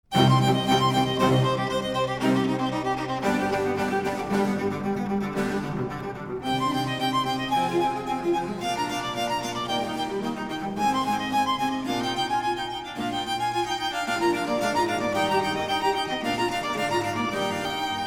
Альт и Скрипка
альт-и-скр.mp3